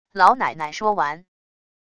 老奶奶说完wav音频